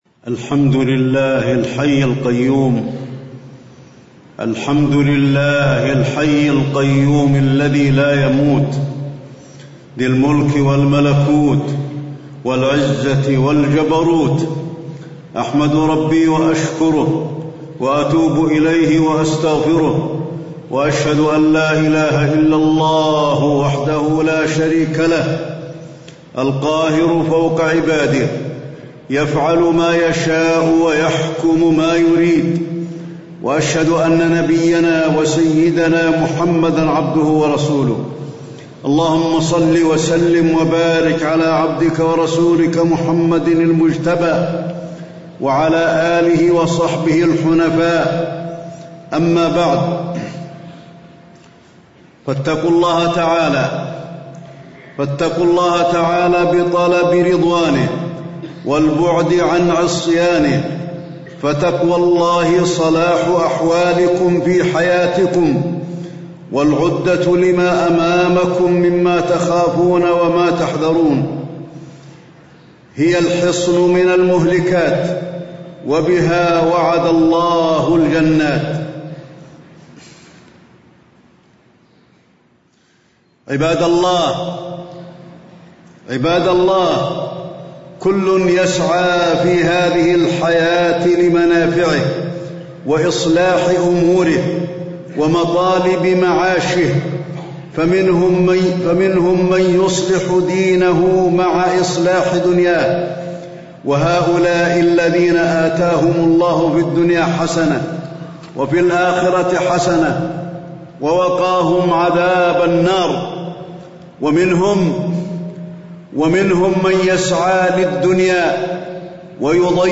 تاريخ النشر ٢٢ رجب ١٤٣٧ هـ المكان: المسجد النبوي الشيخ: فضيلة الشيخ د. علي بن عبدالرحمن الحذيفي فضيلة الشيخ د. علي بن عبدالرحمن الحذيفي الاستعداد للموت